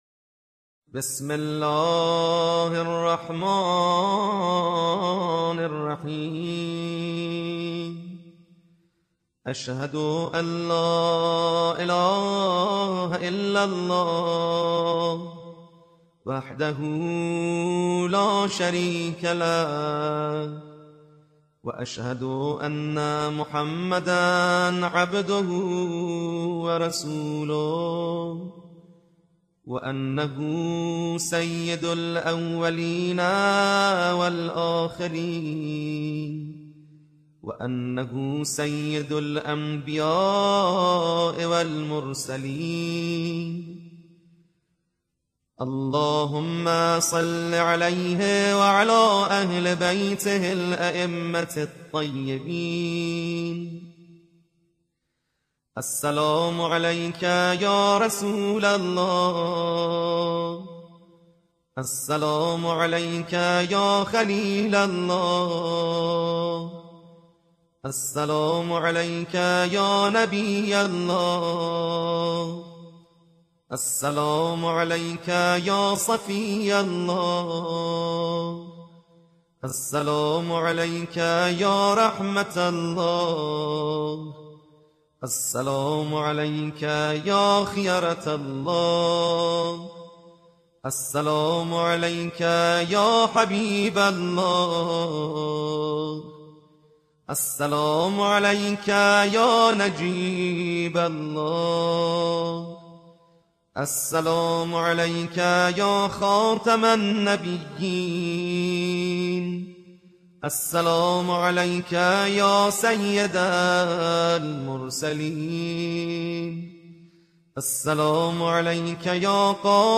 زيارة النبي الأكرم كاملة بصوت القارئ الايراني